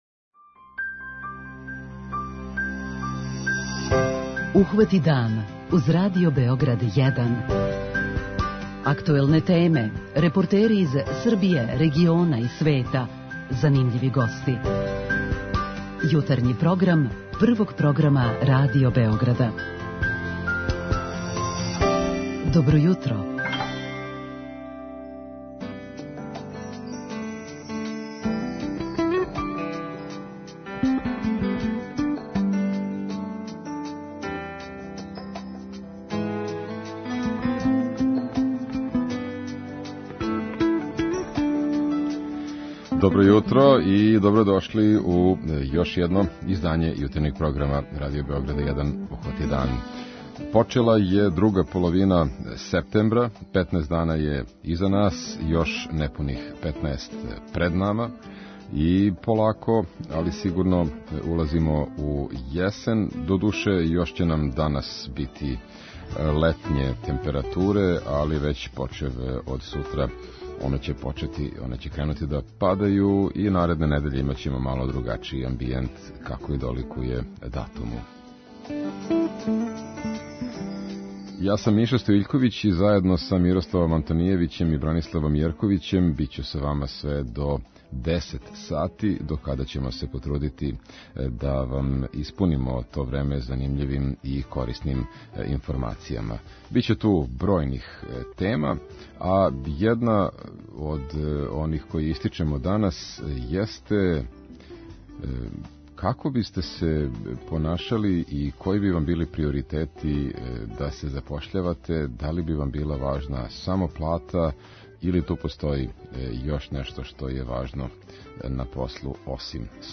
А као увод у овај разговор, мало ћемо попричати и са слушаоцима. У оквиру наше редовне рубрике 'Питање јутра' питаћемо их шта је њима најважније на послу - плата, атмосфера, међуљудски односи, могућности усавршавања и напредовања...?